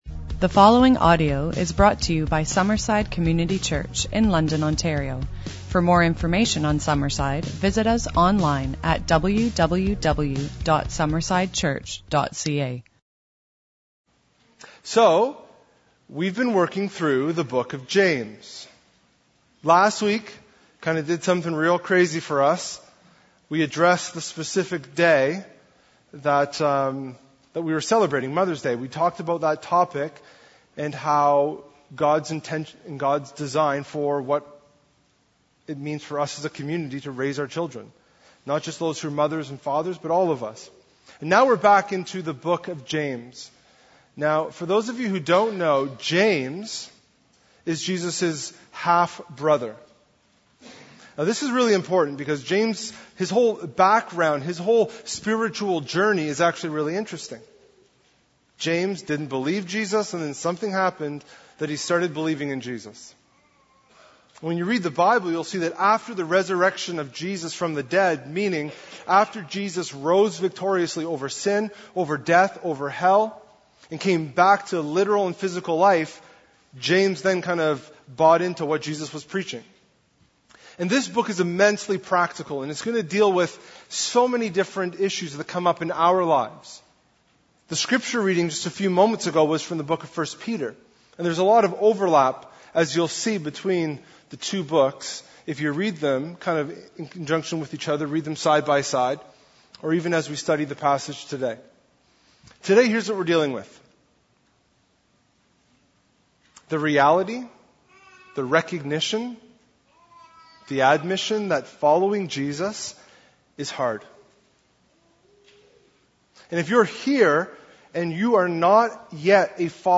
It’s easy to get discouraged, beaten down, or lose faith. In Sunday’s sermon, we’re looking at three incredible truths that can make our hearts glad.